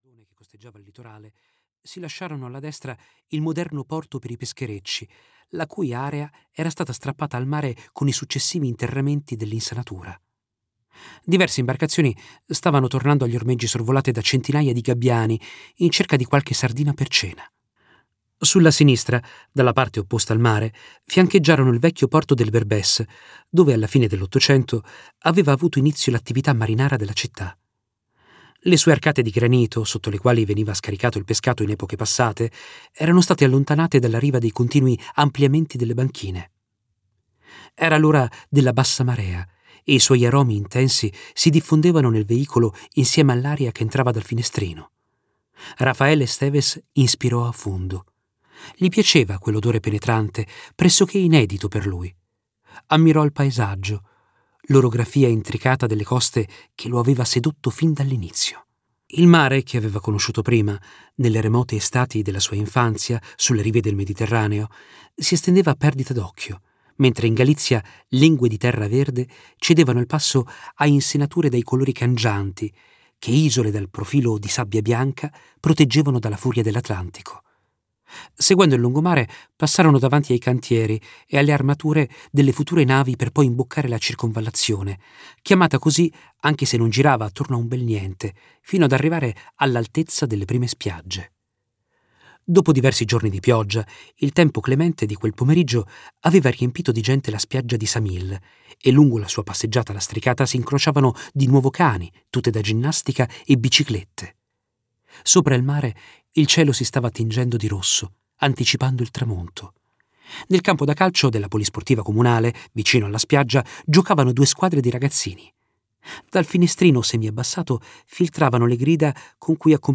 "Occhi d'acqua" di Domingo Villar - Audiolibro digitale - AUDIOLIBRI LIQUIDI - Il Libraio